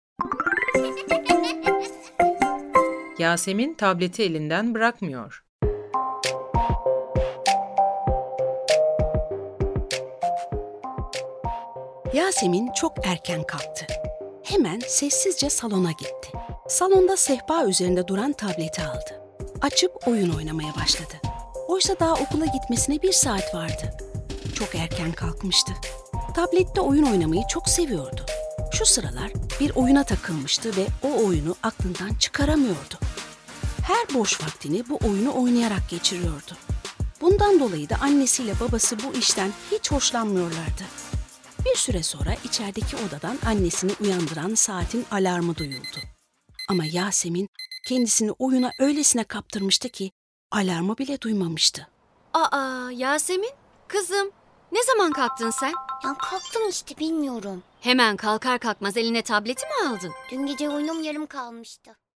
Yasemin Tableti Elinden Bırakmıyor Tiyatrosu